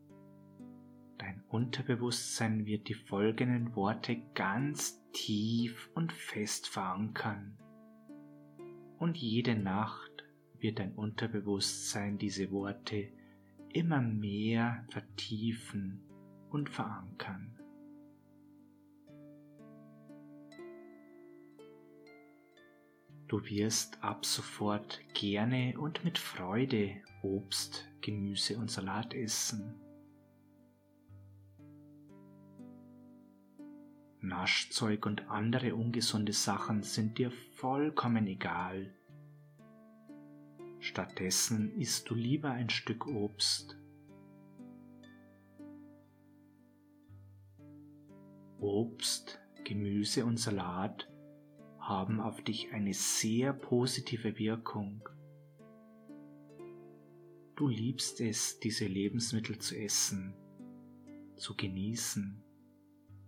Diese geführte Hypnose ist genau das Richtige für Sie, wenn Sie sich wieder gesünder und bewusster ernähren wollen! Nach einer wunderbaren Einleitung, gefolgt von einer tiefen Entspannung werden in Ihrem Unterbewusstsein Affirmationen verankert, so dass Sie in Zukunft gerne und mit Genuss Obst, Gemüse und Salat essen werden sowie auf ungesundes Naschzeug verzichten.